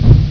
mallet.wav